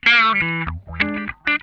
CRUNCHWAH 9.wav